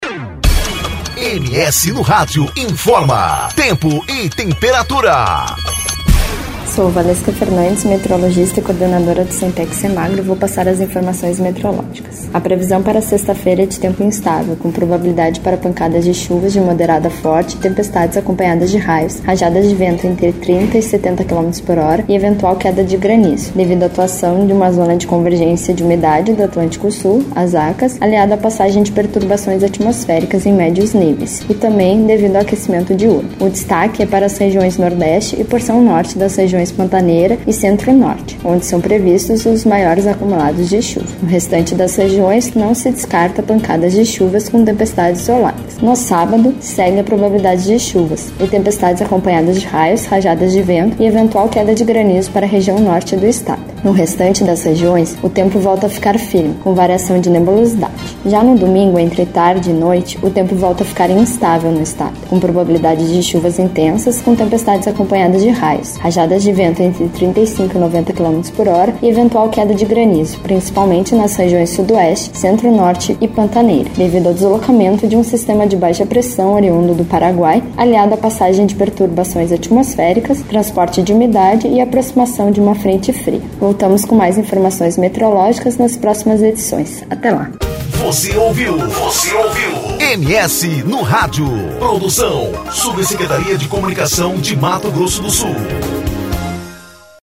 Previsão do Tempo: Final de semana com pancadas de chuvas e possibilidade de tempestades